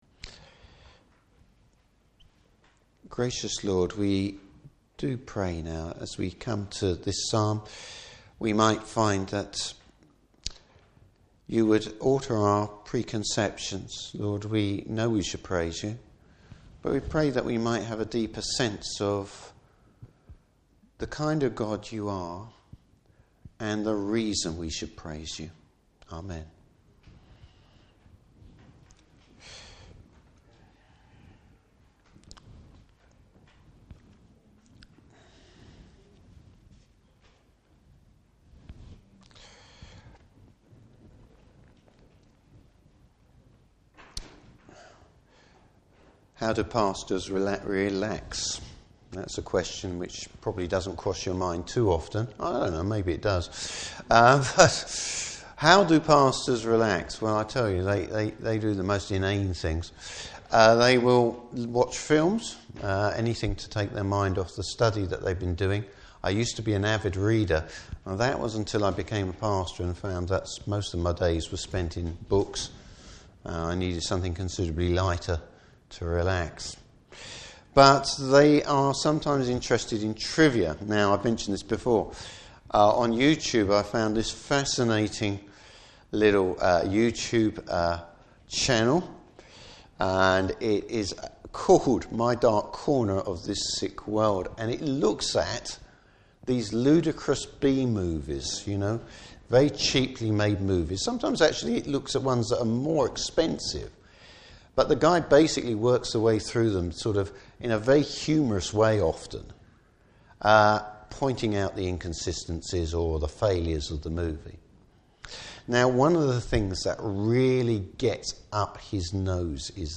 Service Type: Morning Service The forgiving God!